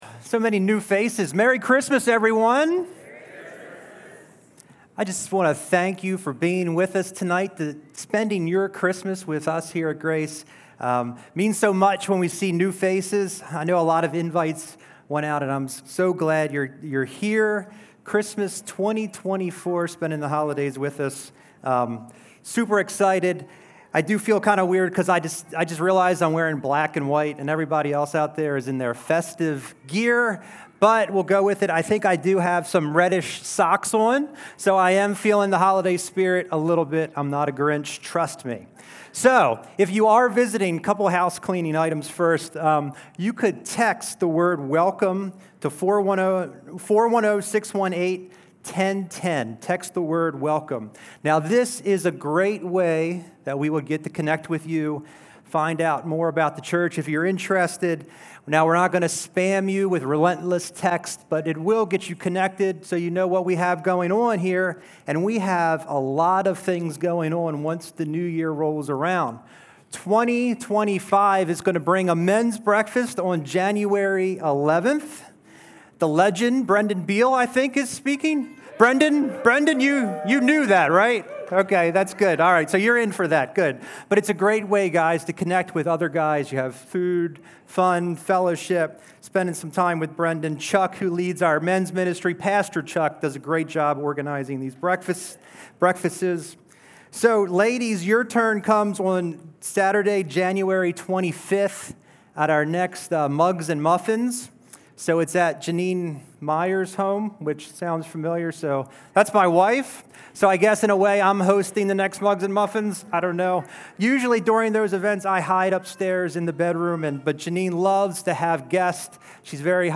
Messages from Grace Community Church in Kingsville, MD